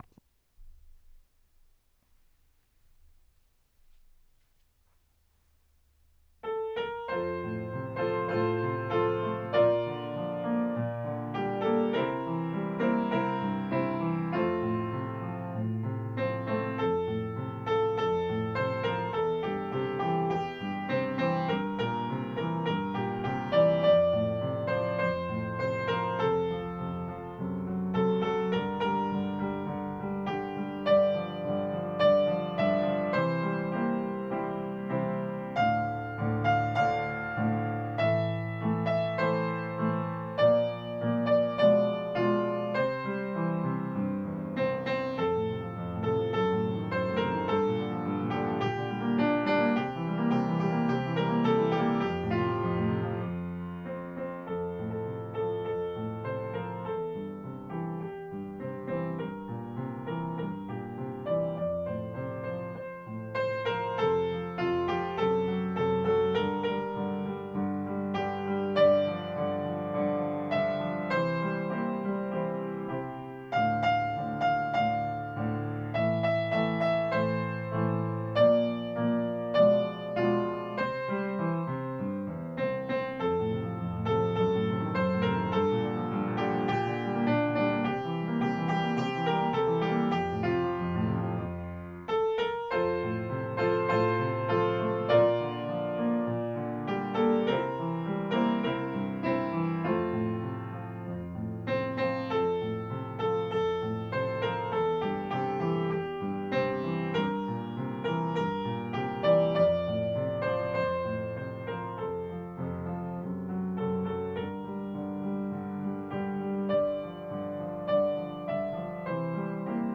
Bản nhạc và trang âm thanh bài hát hợp xướng "I Love Aoba Ward"
Ngoài ra còn có bản ghi âm của từng phần cho điệp khúc đơn ca và điệp khúc hỗn hợp bốn phần.
Bài tập hát "I Love Aoba Ward" (tệp nhạc (MP3): 37,712KB)